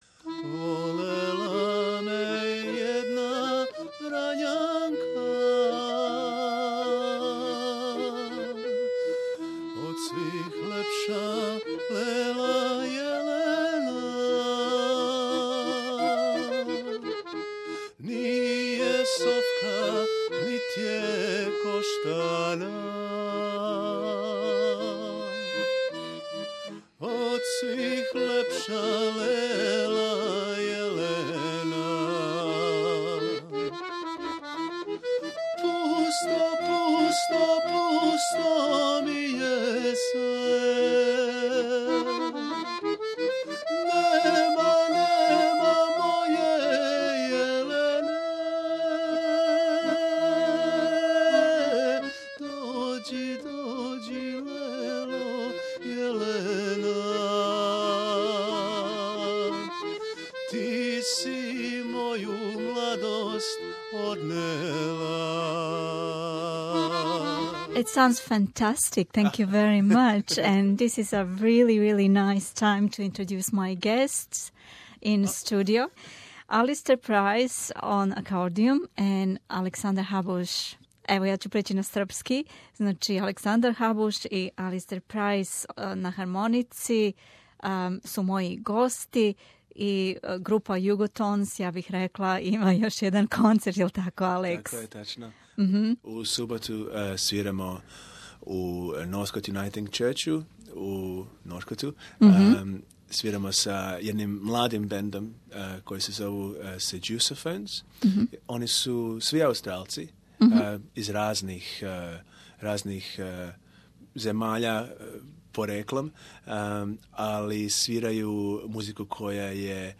Придружили су нам се у студију да причамо о новим наступима и широком интерсовању за балканску музику у Аустралији...